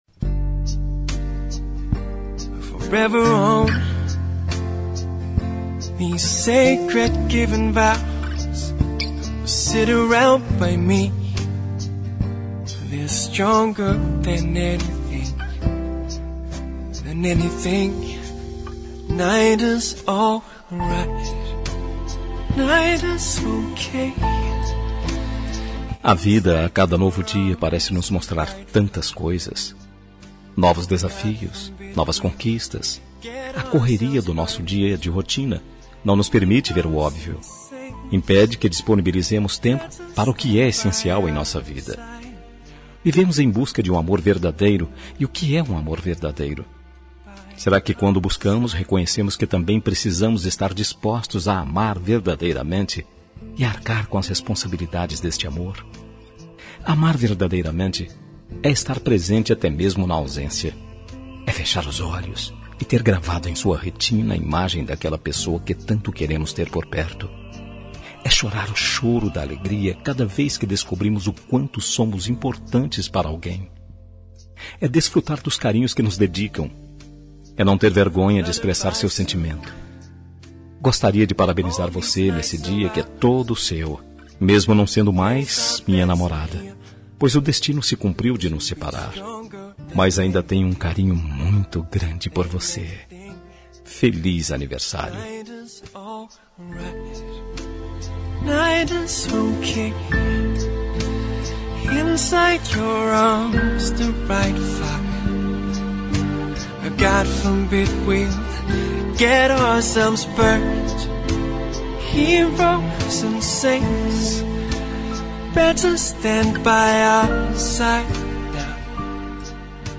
Telemensagem de Aniversário de Ex. – Voz Masculina – Cód: 1371